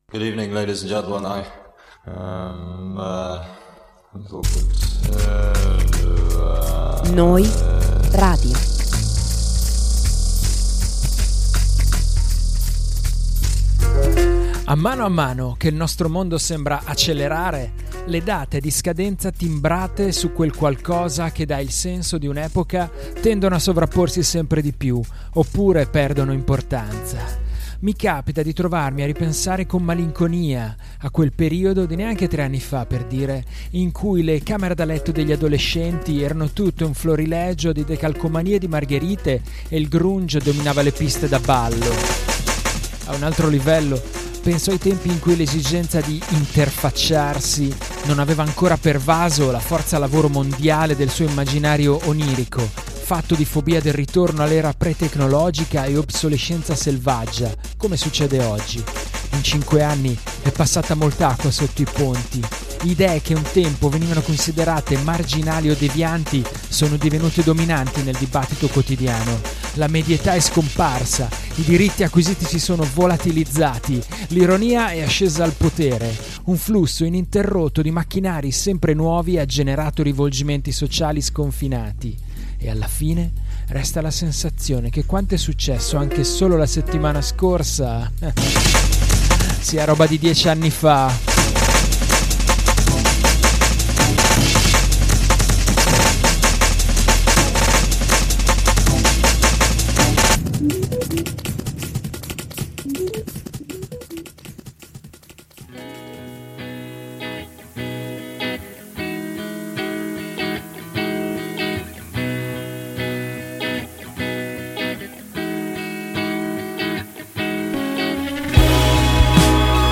Podcast di novità indiepop, indie rock, shoegaze, post-punk, lo-fi e twee!